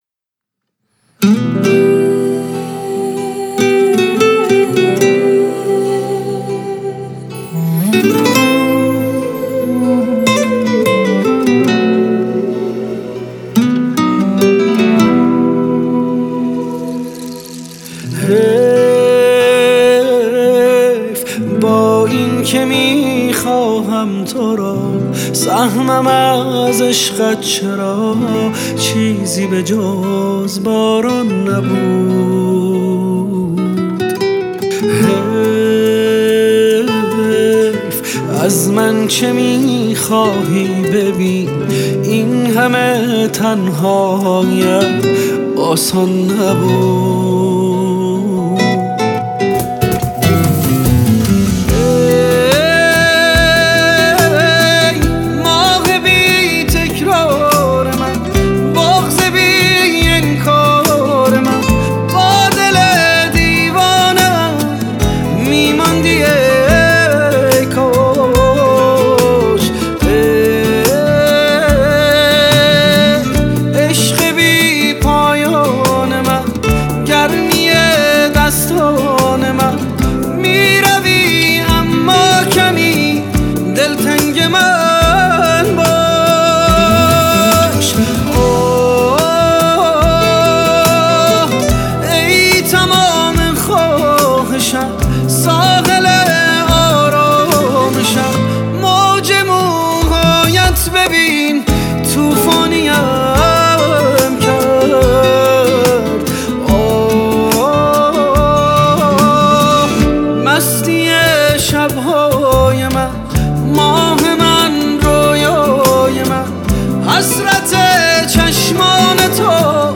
غرق در این ملودی عاشقانه و بی‌نظیر شو.